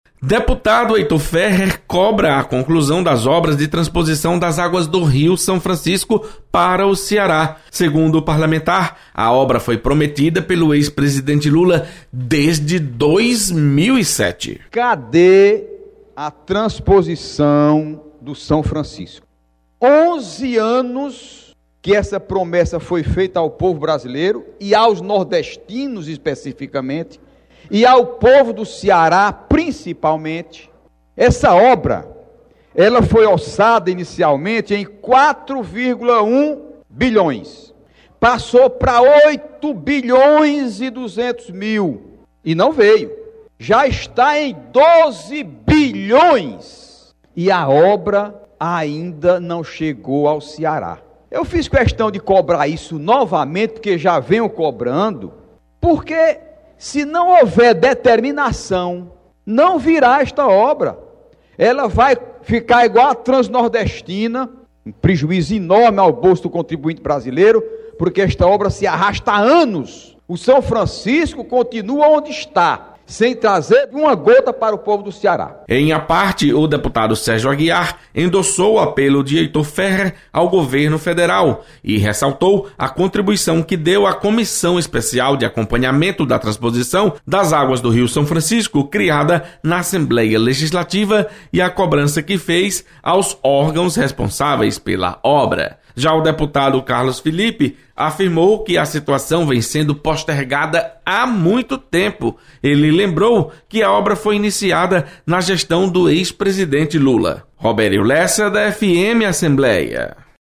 Deputados cobram conclusão das obras de transposição do rio São Francisco. Repórter